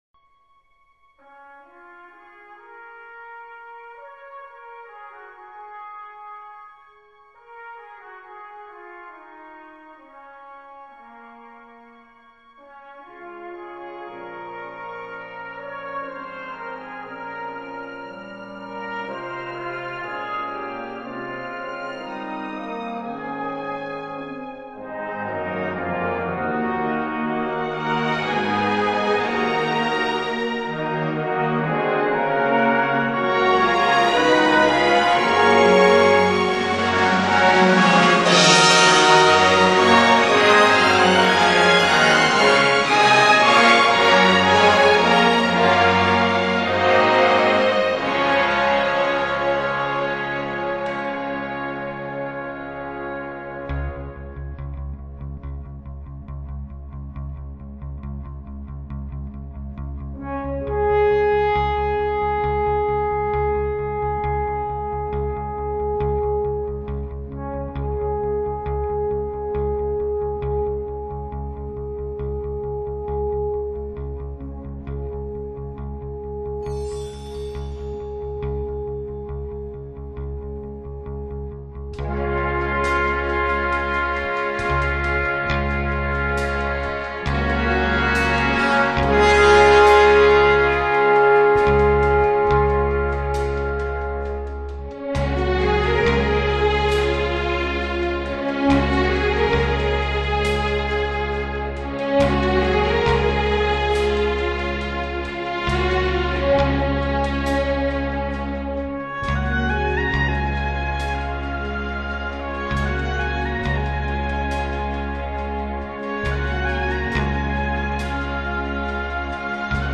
在这里，他巧妙地依靠管弦乐队中的弦乐器，找到了一种富有特殊色彩的音响，这种富有特色的弦乐演奏，使他的音乐流传世界各地。